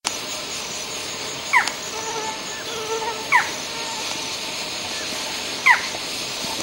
Falcão-caburé (Micrastur ruficollis)
Nome em Inglês: Barred Forest Falcon
Fase da vida: Adulto
Localidade ou área protegida: Parque Nacional Chaco
Condição: Selvagem
Certeza: Fotografado, Gravado Vocal